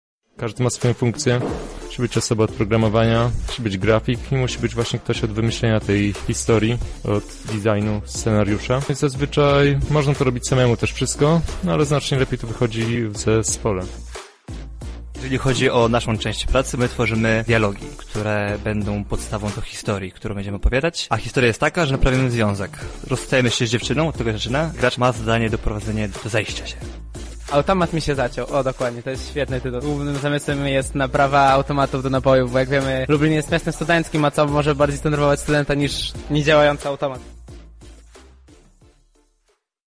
Uczestnicy opowiedzieli o swoich zmaganiach.